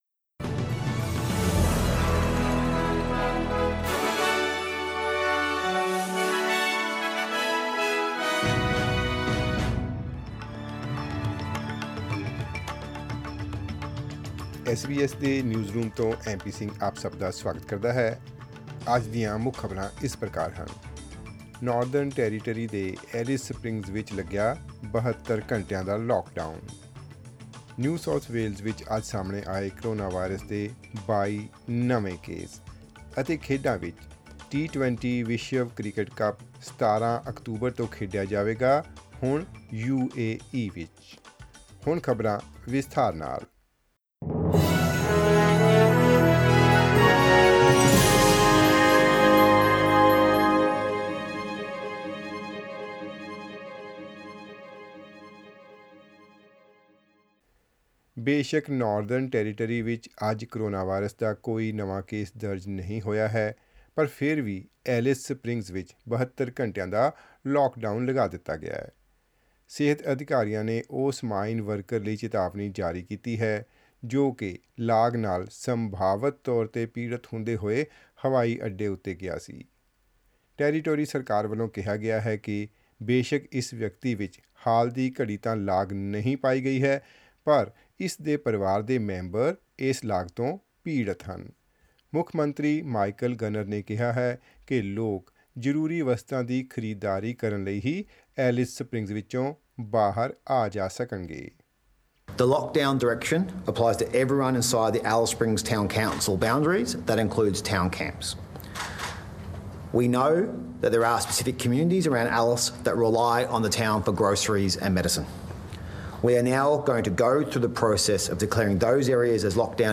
Click on the player at the top of the page to listen to the news bulletin in Punjabi.